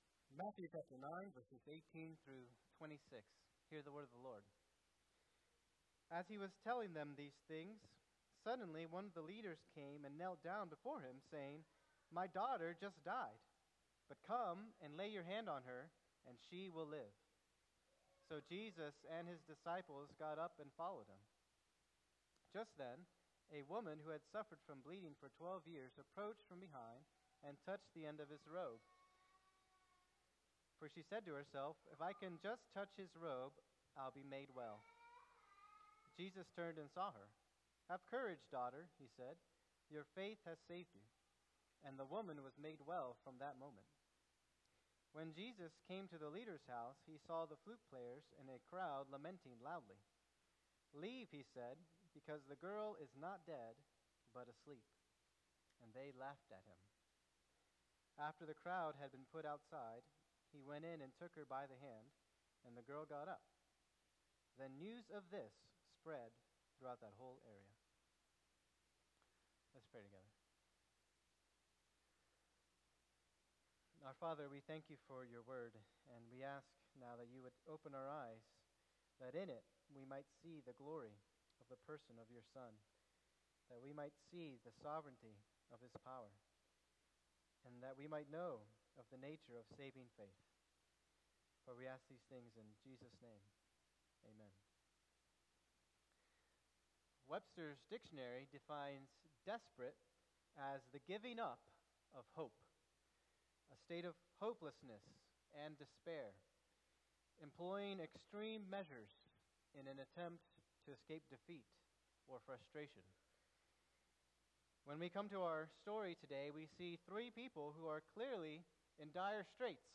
Sermon
2025 at First Baptist Church in Delphi, Indiana.